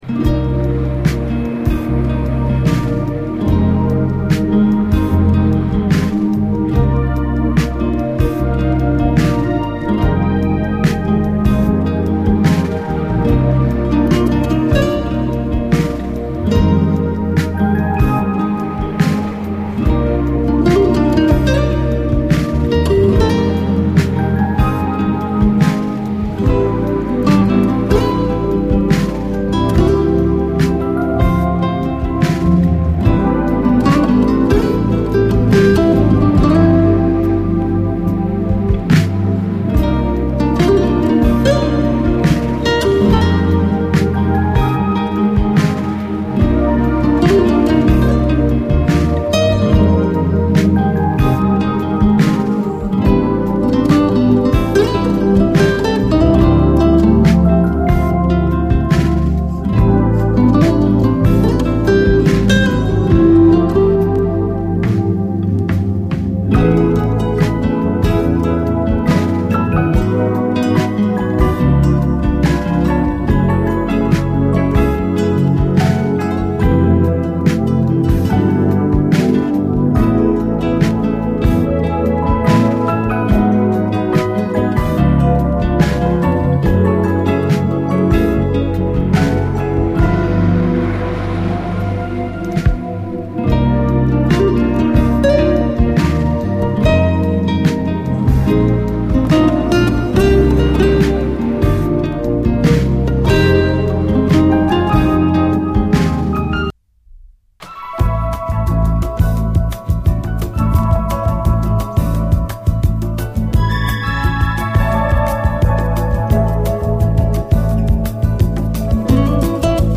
JAZZ FUNK / SOUL JAZZ, JAZZ, LATIN
空間的なシンセ・サウンドが、バレアリック的琴線を刺激する白昼夢メロウ・フュージョン！